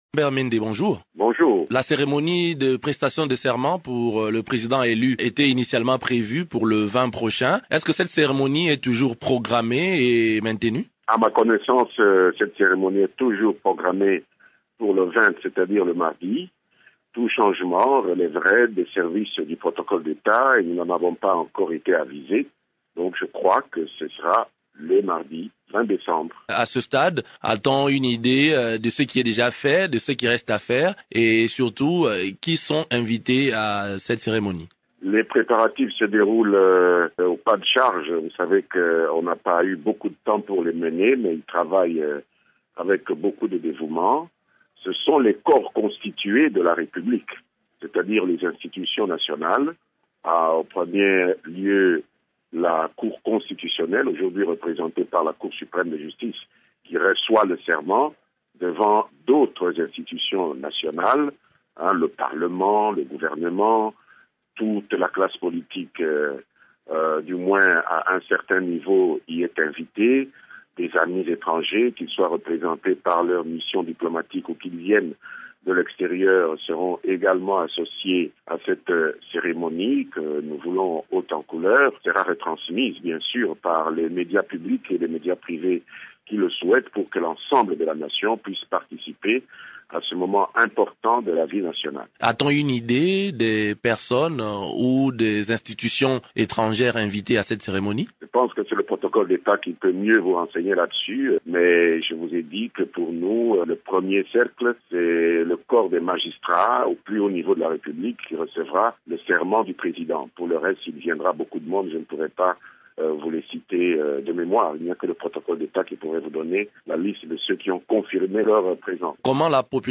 Lambert Mende est interrogé par